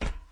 scpcb-godot/SFX/Step/SCP/StepSCP4.ogg at 423912bbded30835f02a319640a5813ecd8cd6ca
StepSCP4.ogg